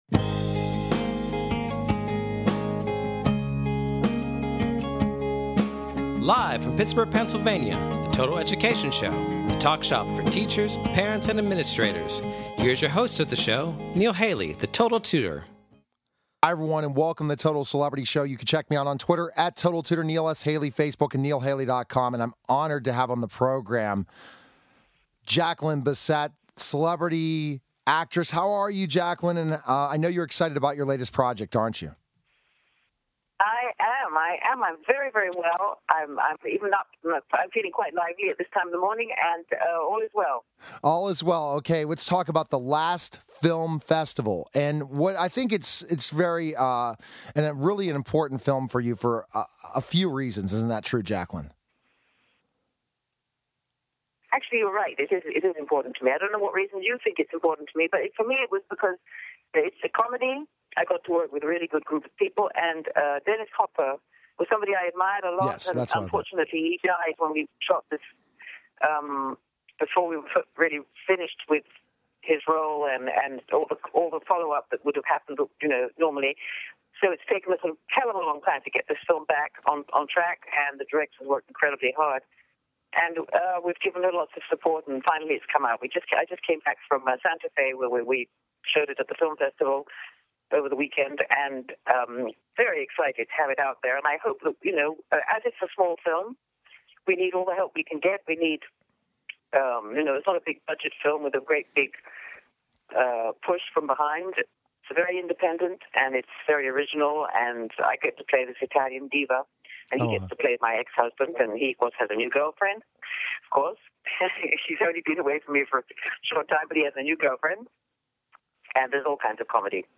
Guests, Jacqueline Bisset, Deidrich Bader, Rapper Too Short, Gabrielle Reece, Bill Moseley, and Matthew Hussey